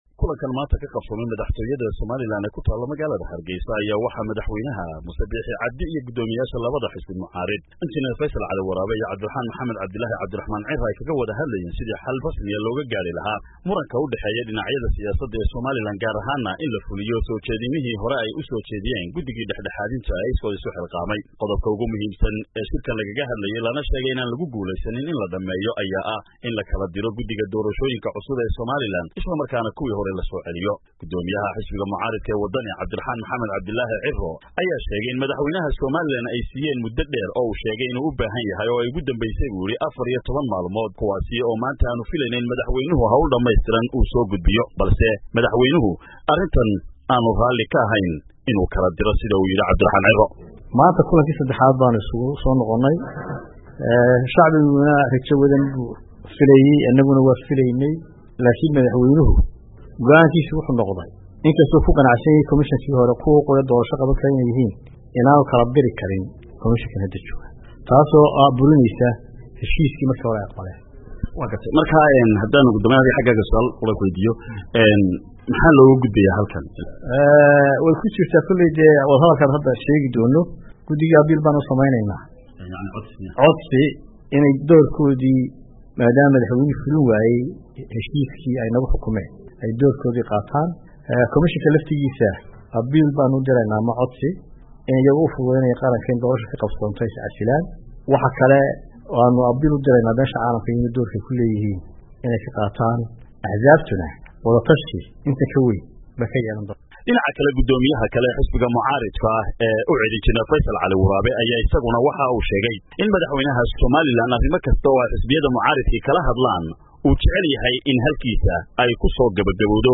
Warbixintan waxa Hargeysa ka soo diray